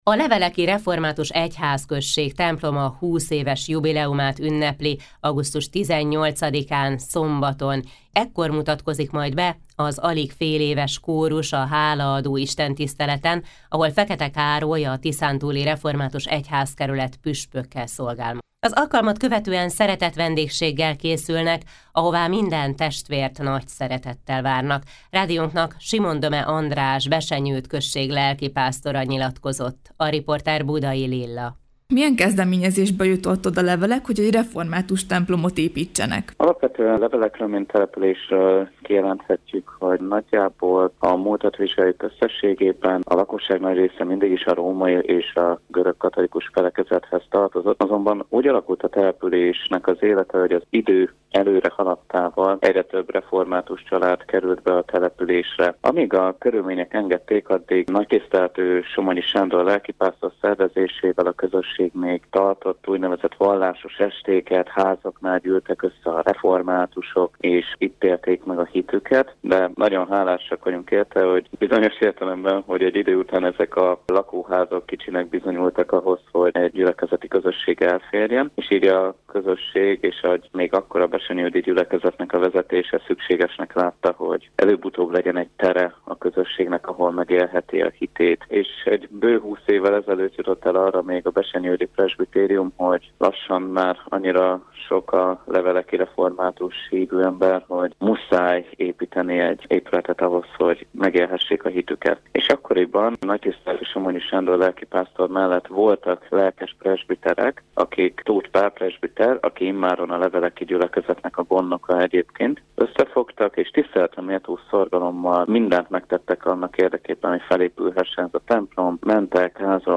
16-42-leveleki-templom.mp3